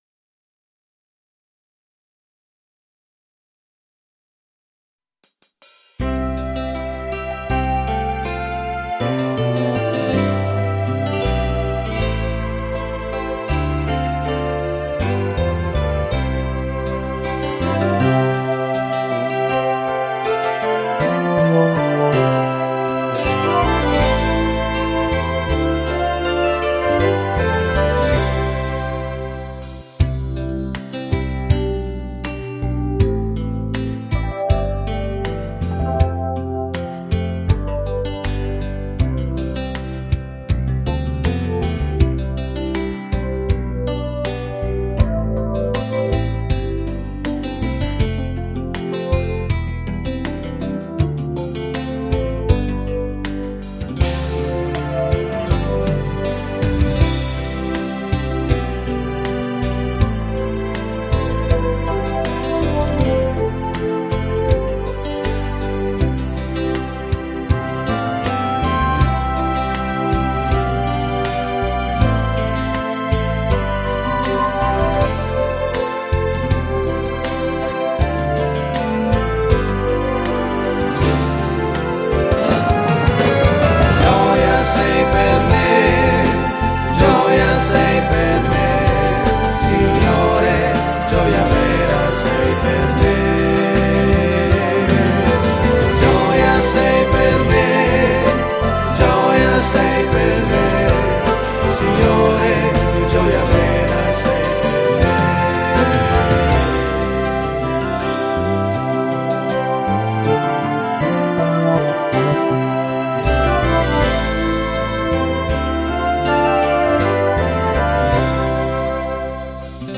Lode ed adorazione